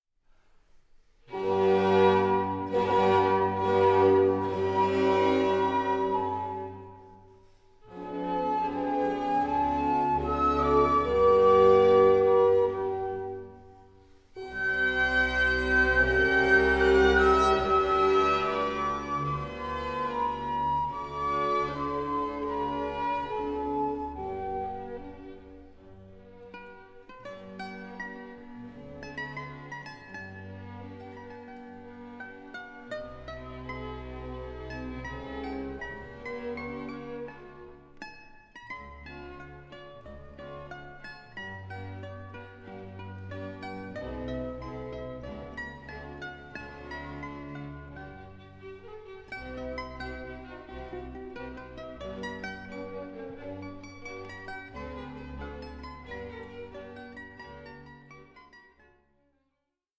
Andantino for Mandolin and Orchestra 2:37